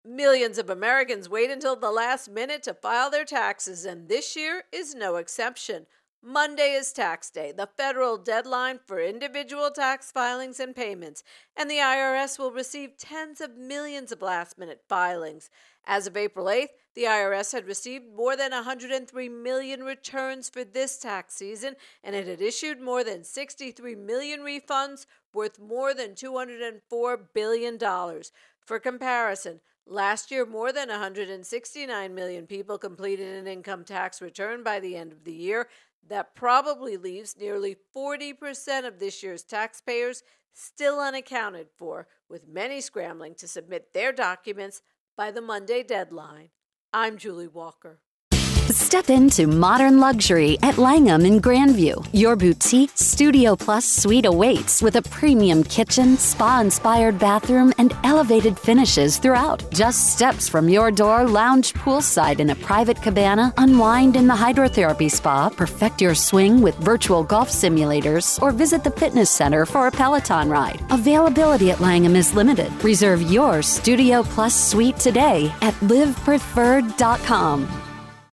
Tax Day intro and voicer